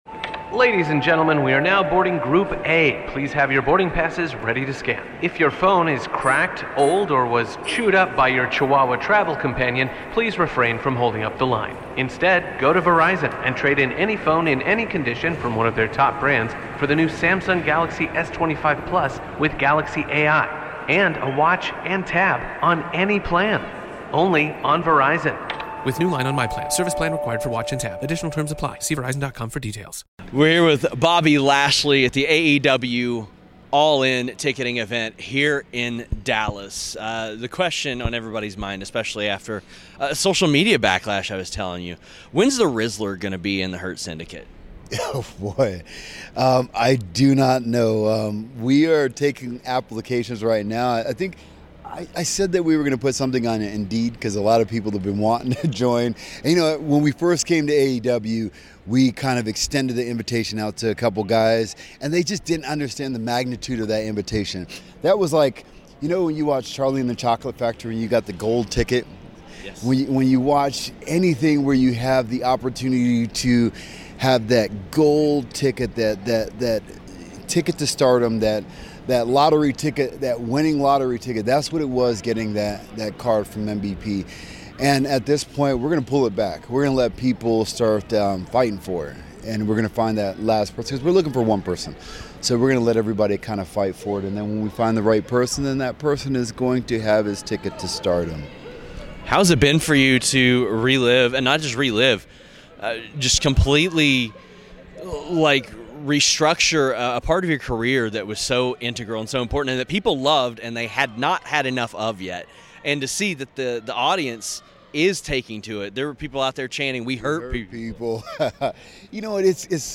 Shoots Interview